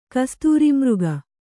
♪ kastūri mřga